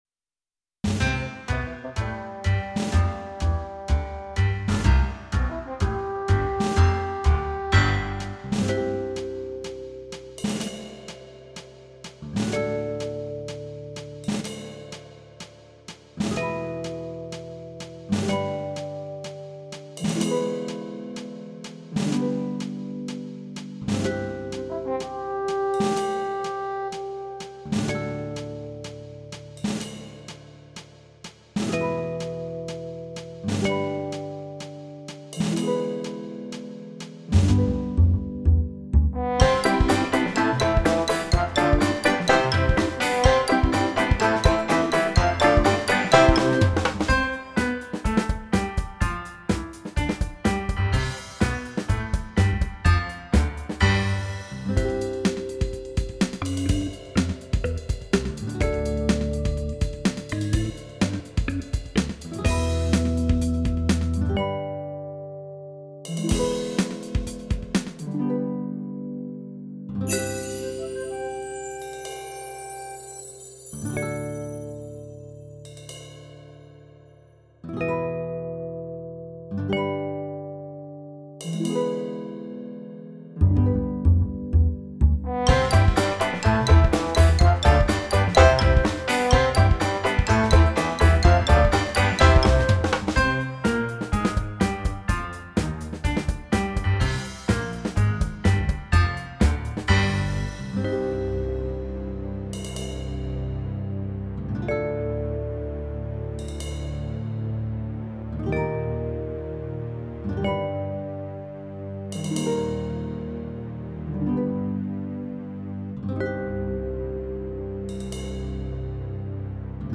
Backing track.